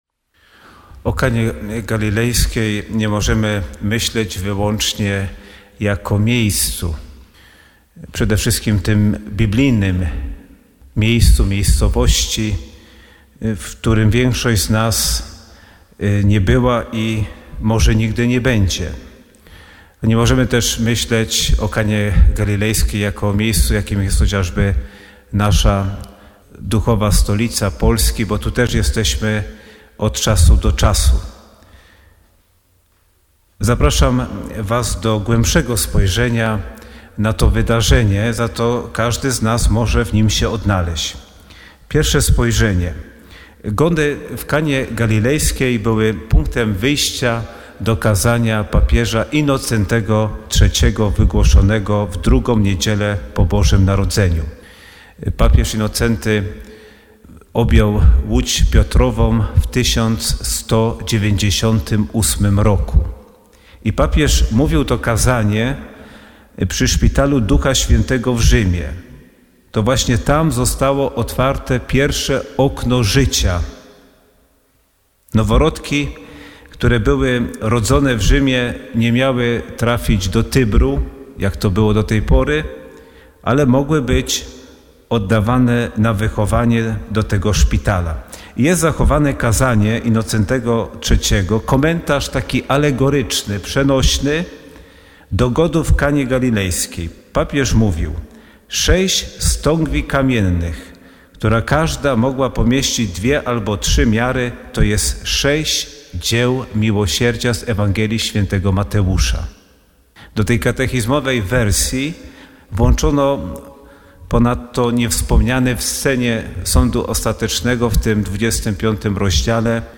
Homilia wygłoszona podczas Mszy św. o 24.00 w kaplicy Matki Bożej Częstochowskiej na Jasnej Górze dn. 3 czerwca 2034